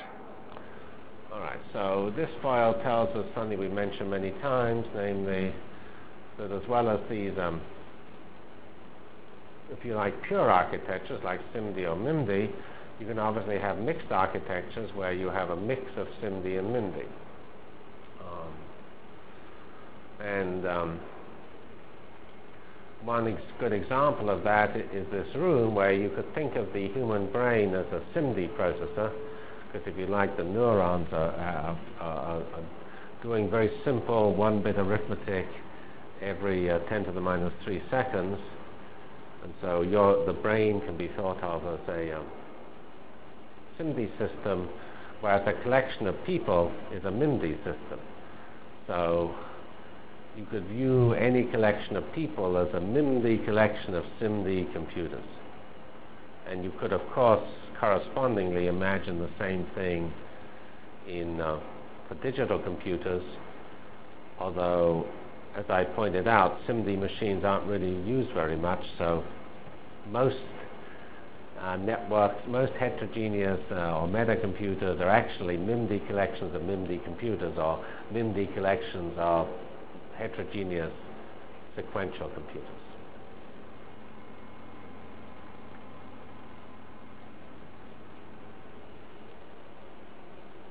From CPS615-Lecture on Computer Architectures and Networks Delivered Lectures of CPS615 Basic Simulation Track for Computational Science -- 12 September 96.